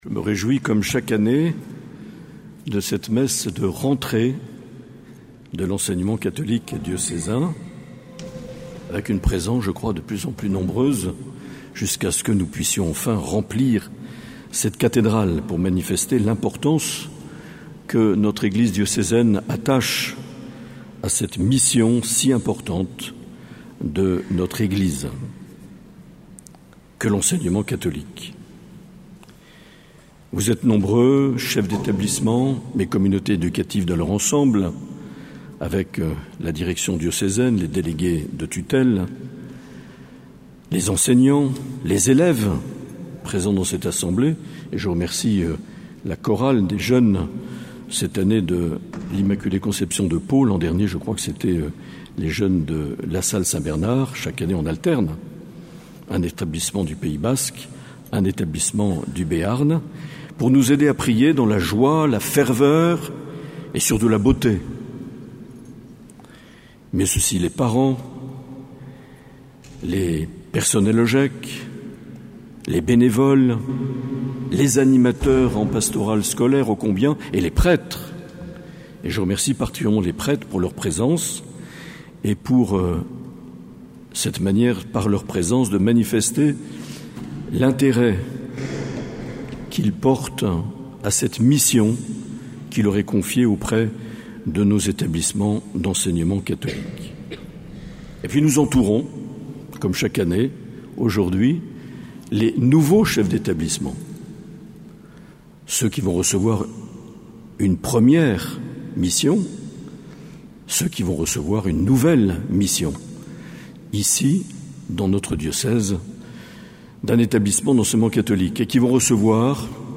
13 septembre 2023 - Cathédrale de Bayonne - Messe de rentrée de l’Enseignement Catholique
Ecouter l’homélie de Mgr Aillet.